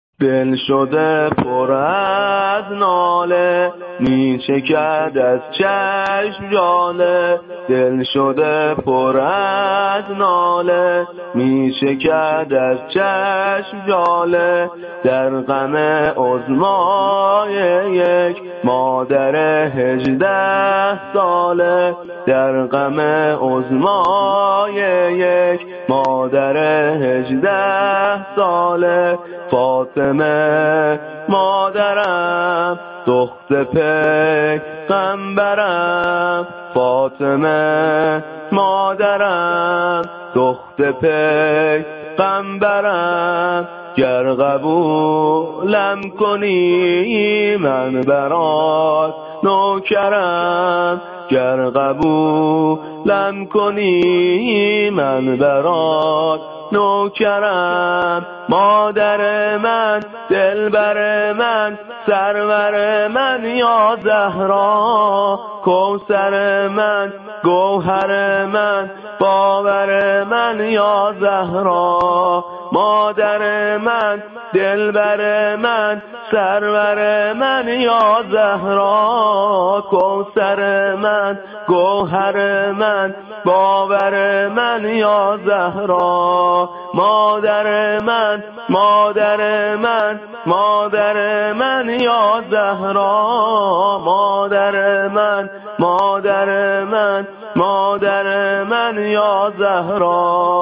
زمینه برای فاطمیه به همراه سبک